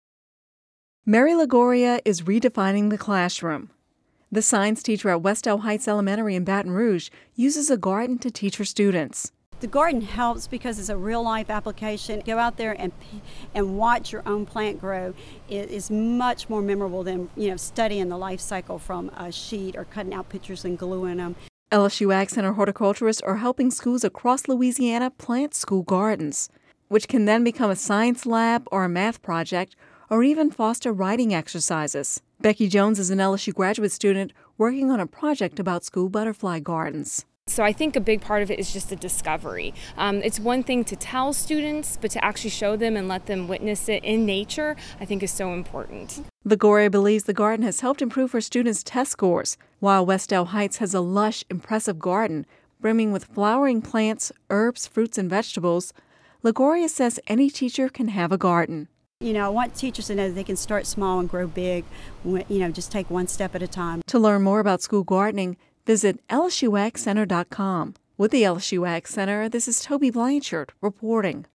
Radio News 05/11/11